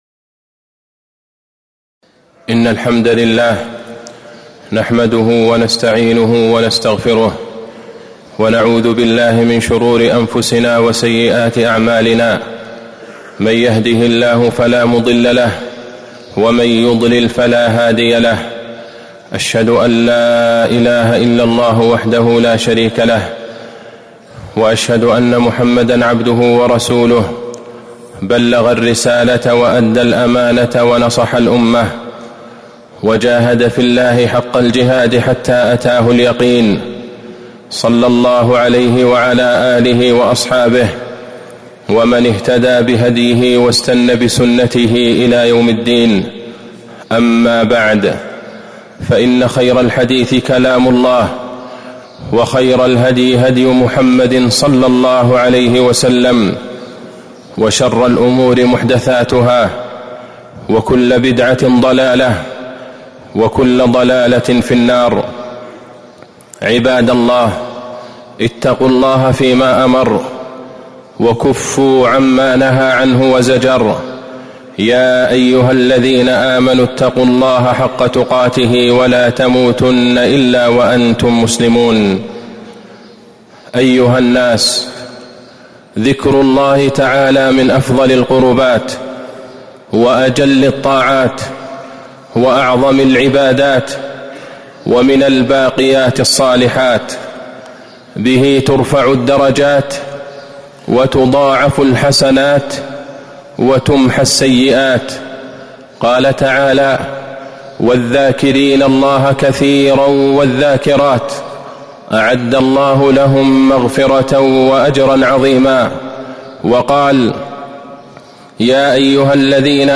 تاريخ النشر ١١ شعبان ١٤٤٤ هـ المكان: المسجد النبوي الشيخ: فضيلة الشيخ د. عبدالله بن عبدالرحمن البعيجان فضيلة الشيخ د. عبدالله بن عبدالرحمن البعيجان من فضائل شهادة أن لا إله إلا الله